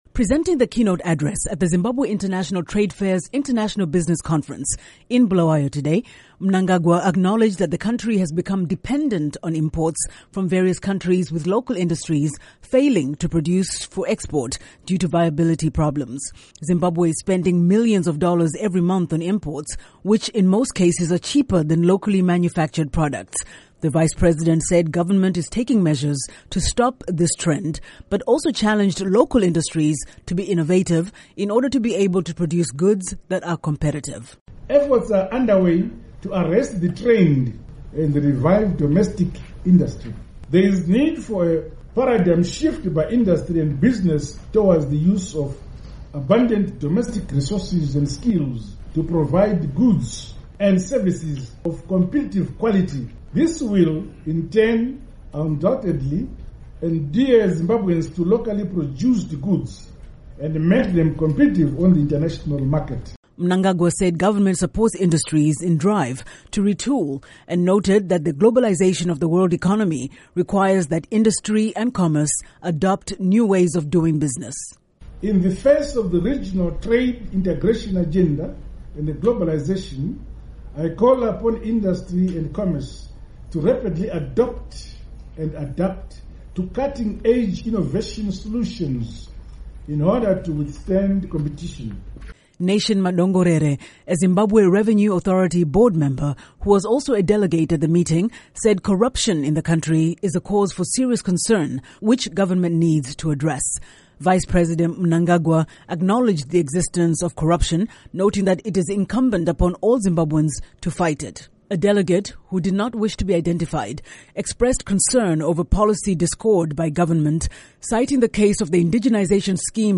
Report on ZITF Business Conference